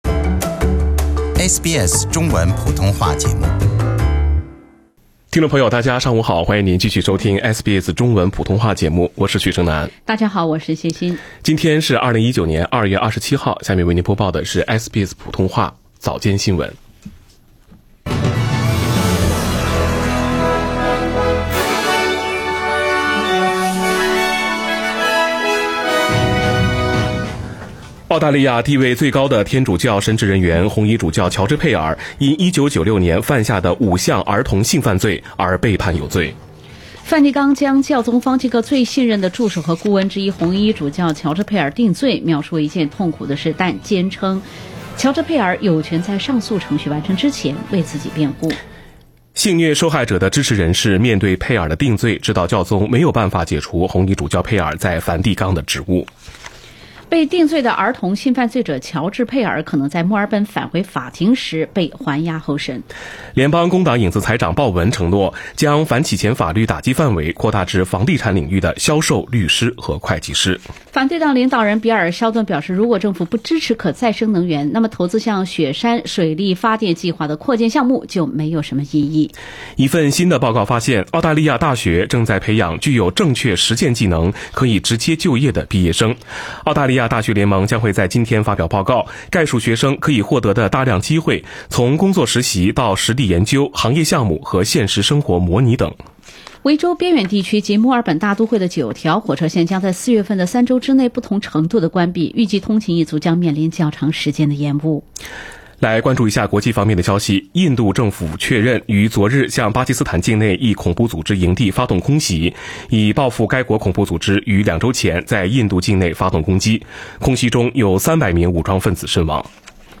SBS Chinese Morning News Source: Shutterstock